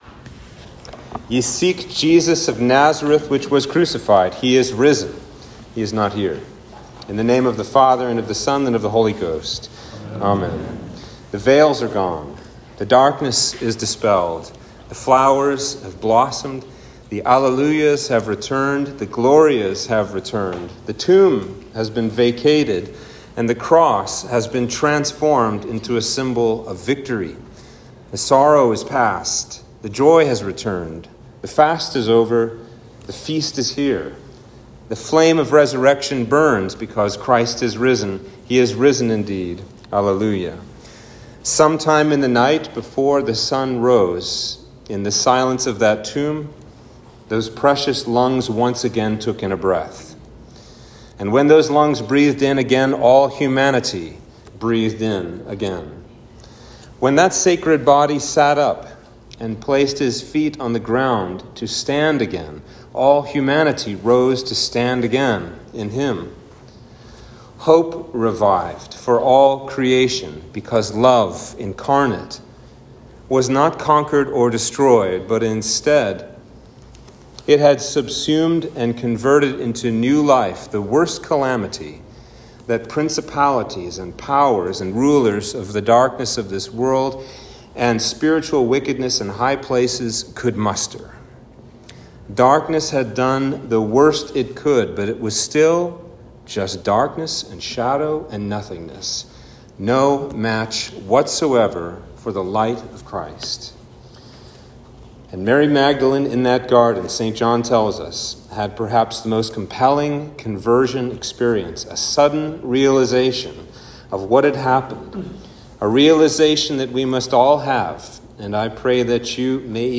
Sermon for Easter Vigil